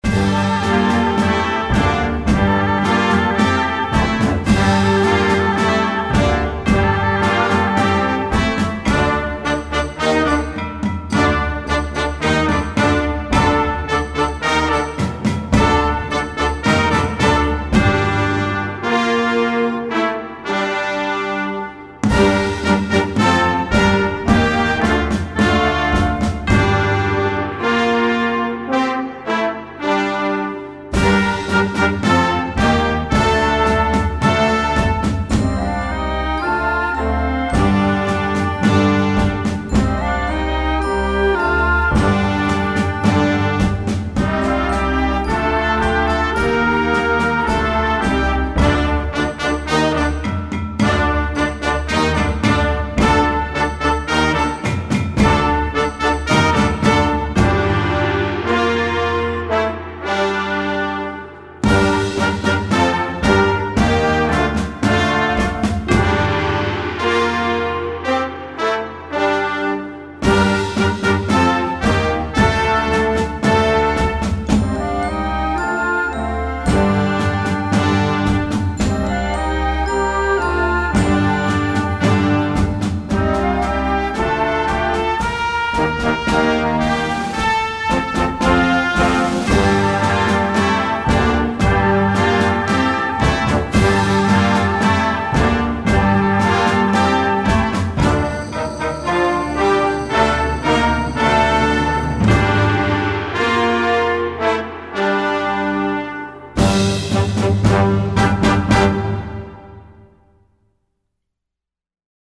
Besetzung: Blasorchester